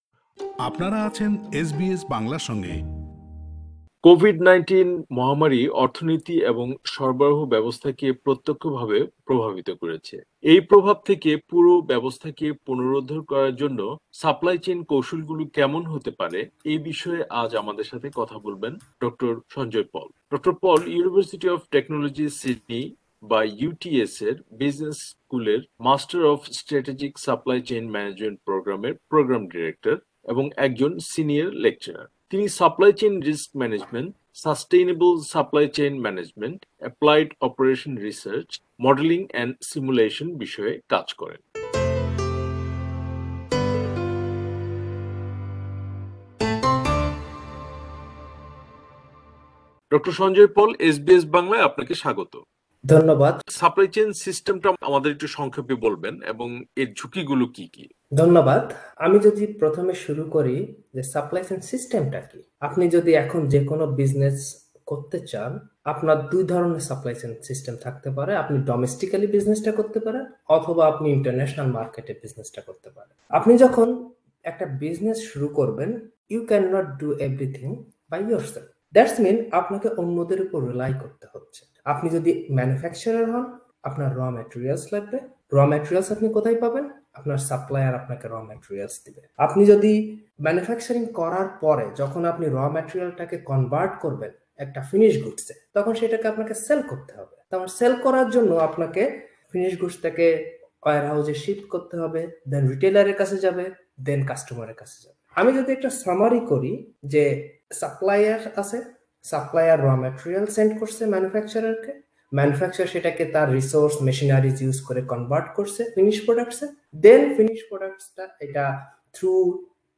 পুরো সাক্ষাৎকারটি